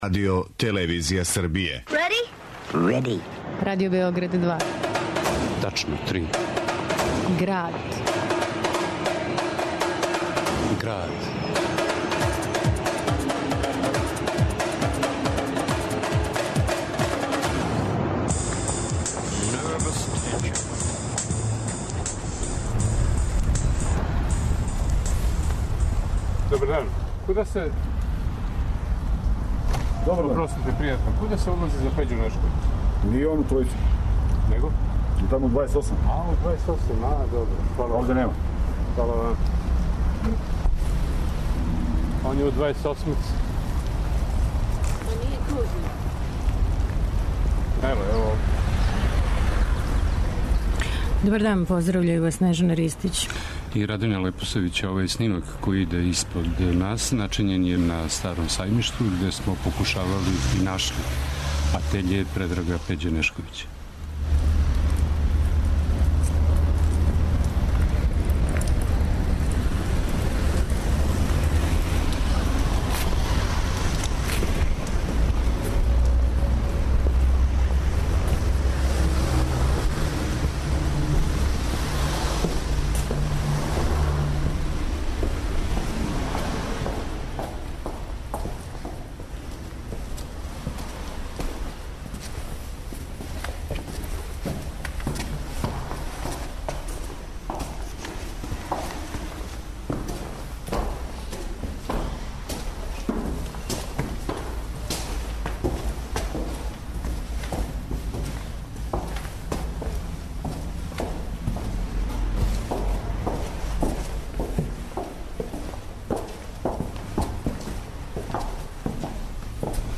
Разговор је снимљен 2012. у његовом атељеу...